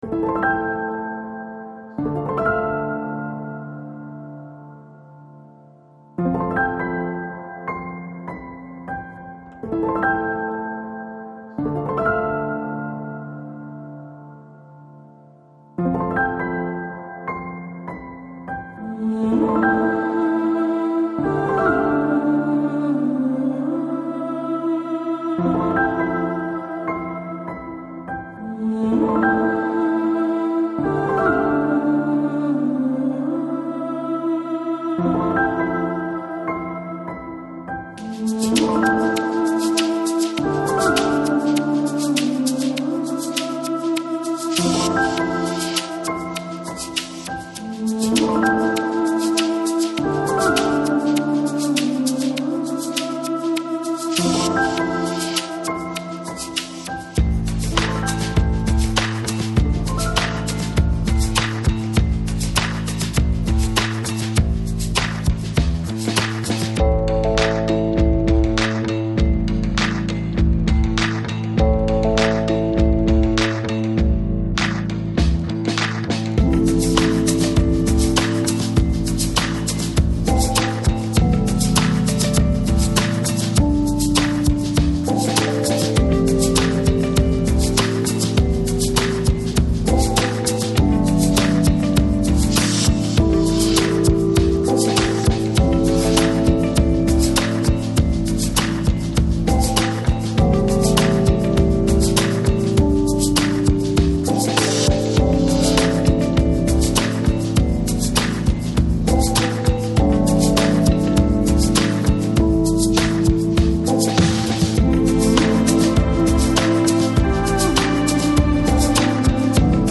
Balearic, Downtempo Издание